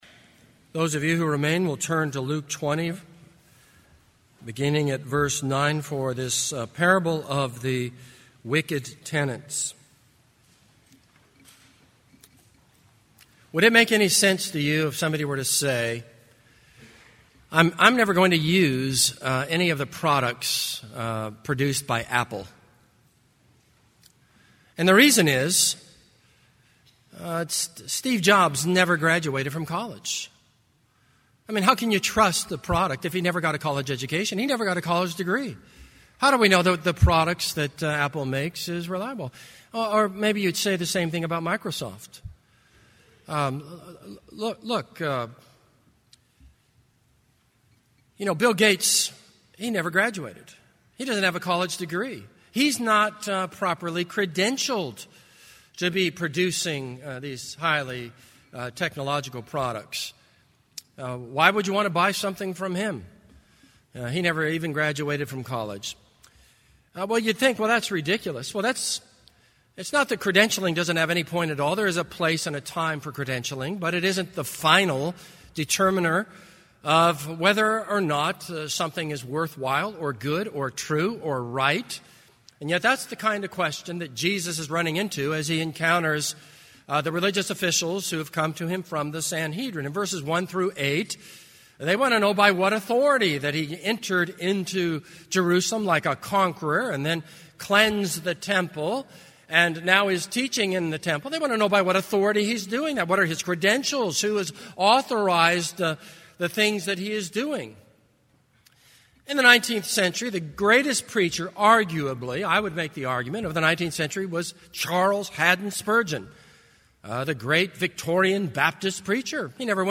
This is a sermon on Luke 20:9-19.